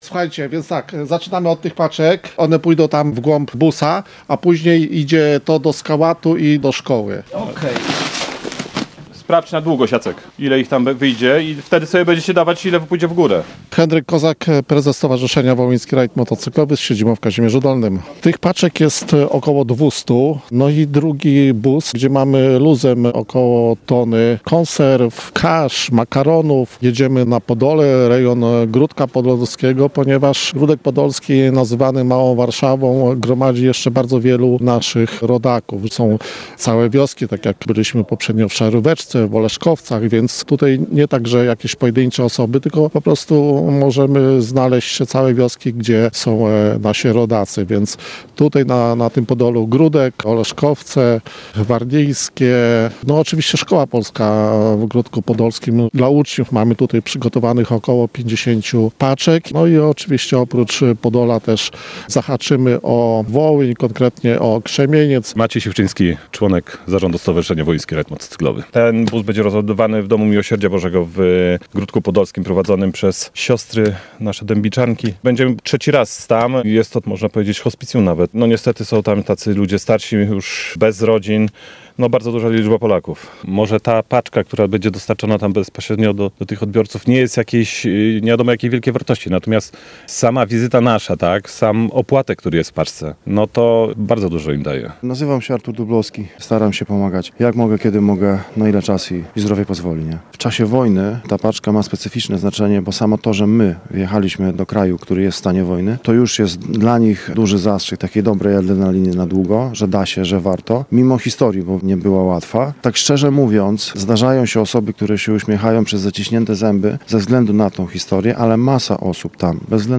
W pakowaniu transportu w magazynie akcji w Witowicach pod Końskowolą uczestniczyliśmy z naszym mikrofonem.